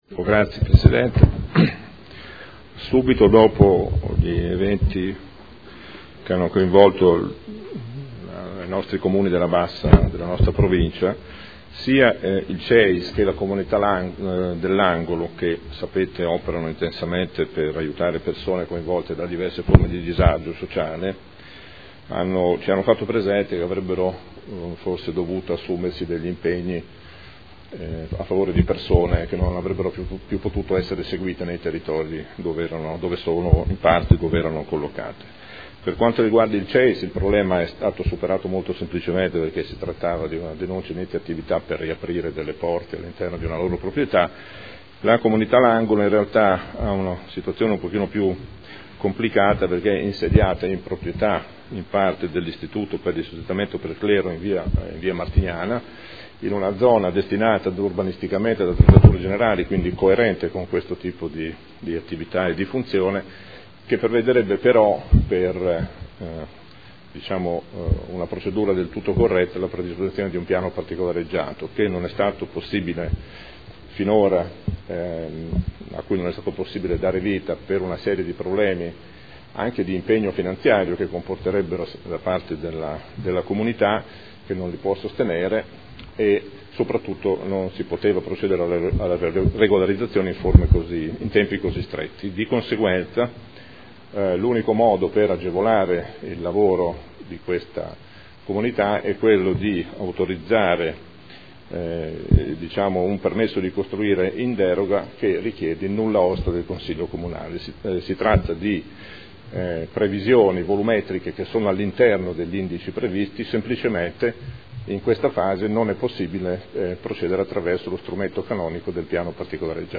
Gabriele Giacobazzi — Sito Audio Consiglio Comunale
Seduta del 18/06/2012.